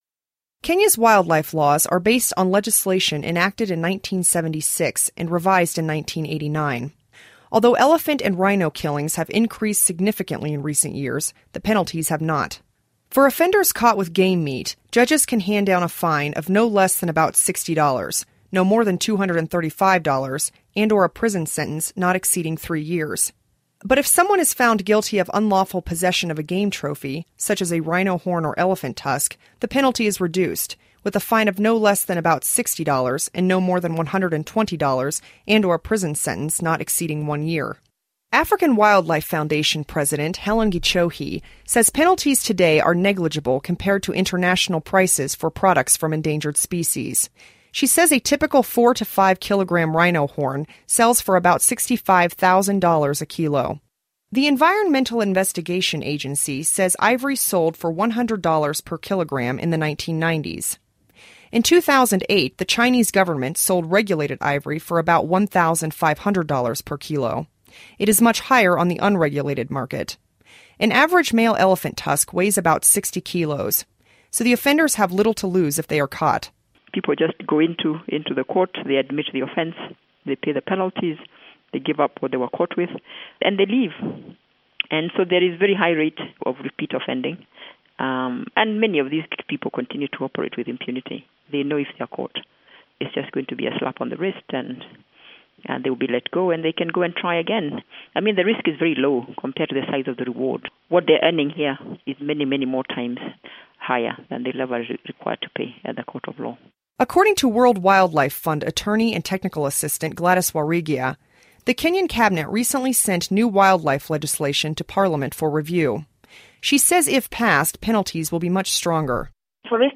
Listen to report on poaching